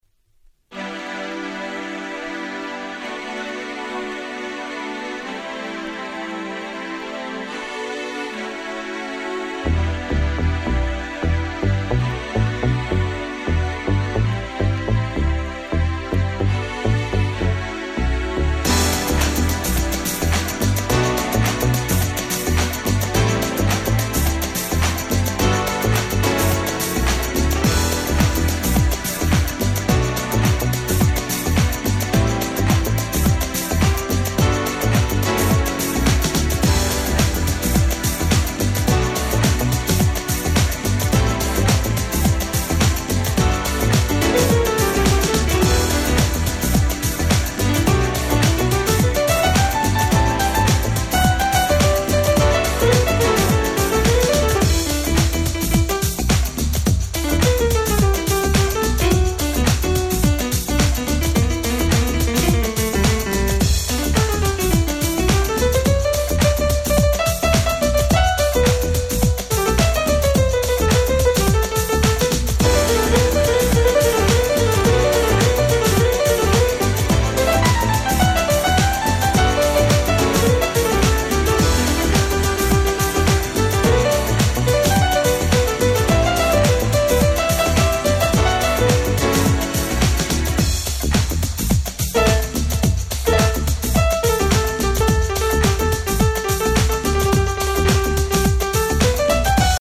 B面収録のピアノインストの美しさたるや！